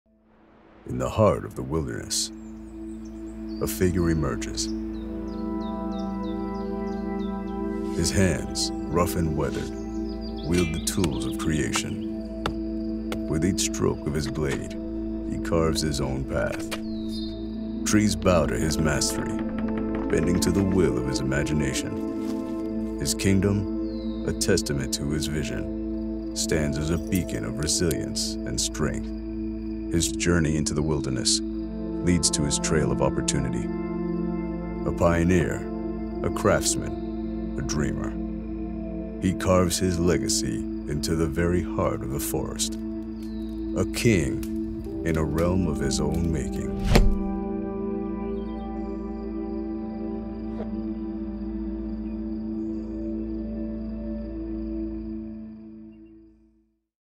Professional American Male Voice Actor | Commercial, E-Learning & Corporate Narration
Commercial Demo
Known for a deep, authoritative voice as well as warm, conversational, and relatable reads, I provide versatile performances tailored to luxury brands, tech explainers, financial narration, medical content, network promos, political campaigns, and cinematic trailers.